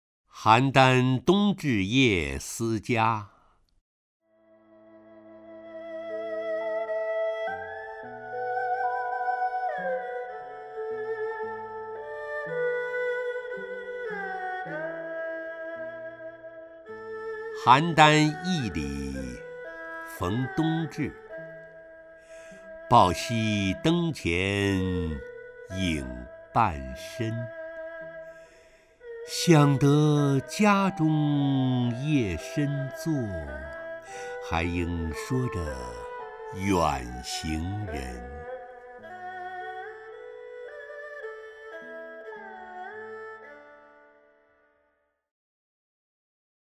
陈醇朗诵：《邯郸冬至夜思家》(（唐）白居易) （唐）白居易 名家朗诵欣赏陈醇 语文PLUS